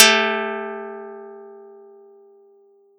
Audacity_pluck_12_14.wav